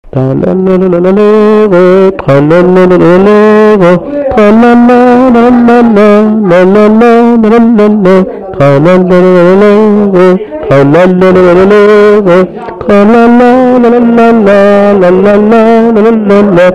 Air de danse
Pièce musicale inédite